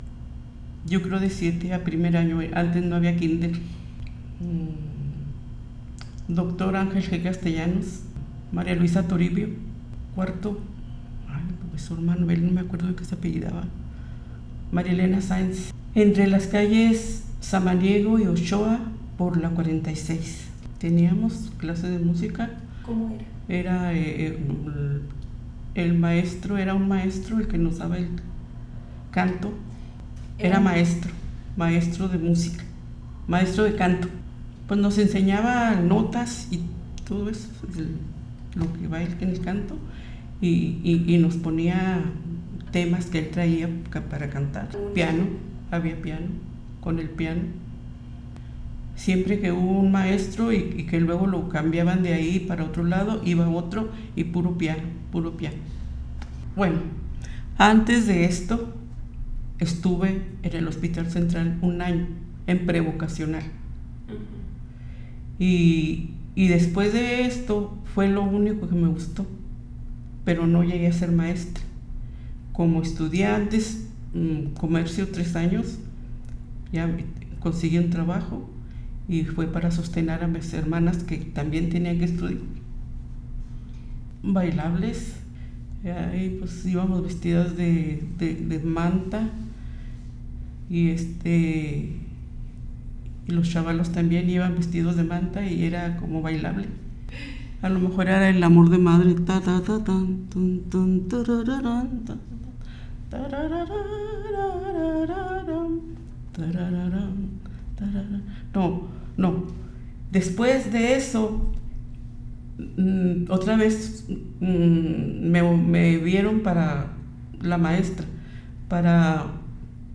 Amor de madre, La varsoviana, La barca de Guaymas, Solo